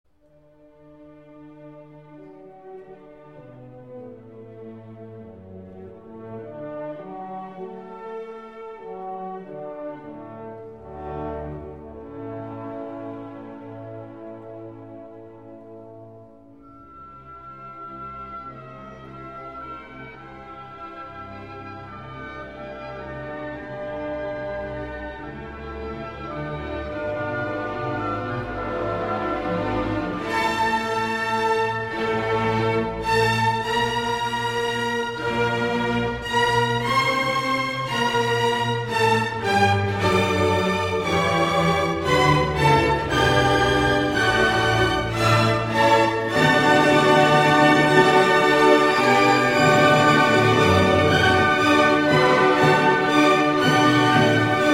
第一乐章不太快的快板Allegro non troppo 3/4拍奏鸣曲式
第一主题首先安排由低音色彩乐器唱出，上下来回的古典风乐句，第二句0:19让木管接棒，是平稳乐句，再来则由弦乐以大跳音程演奏出强烈个性的转换，第四句0:48是复合乐句，由大提琴呈现第一乐句于低音声部，高音声部仿效第二乐句的音型出现，声部多元，主题原型显得模煳，此效果应是作曲家的精心设计。
乐团主题一的四句